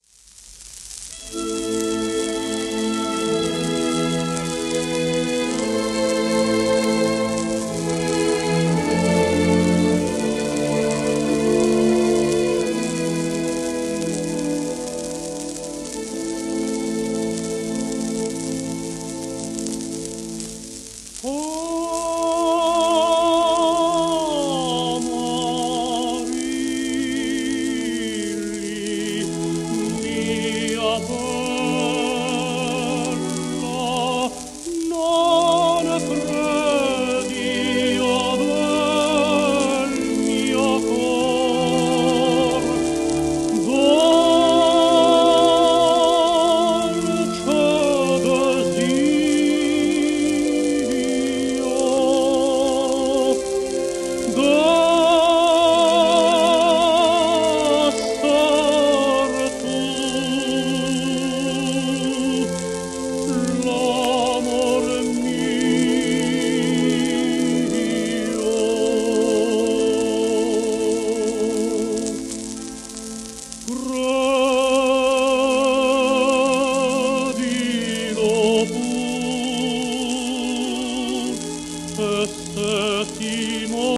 w/オーケストラ
シェルマン アートワークスのSPレコード